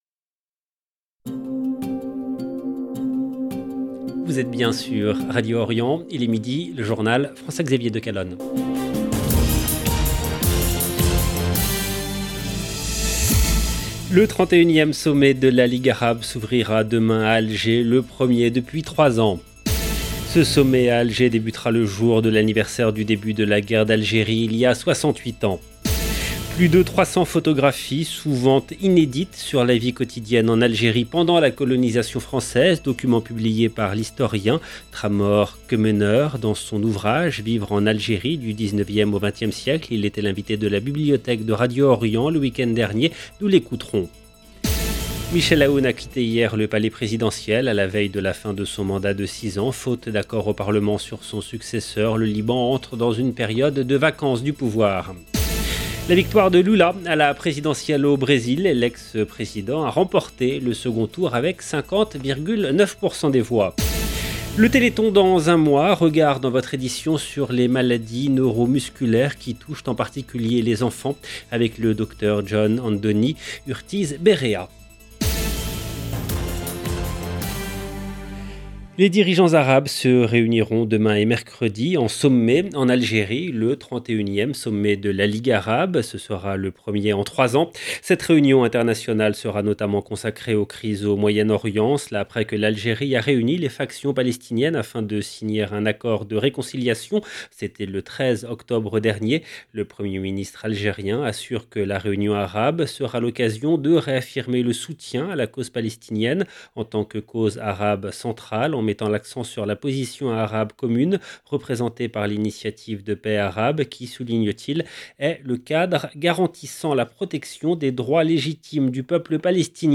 EDITION DU JOURNAL DE 12 H EN LANGUE FRANCAISE DU 31/10/2022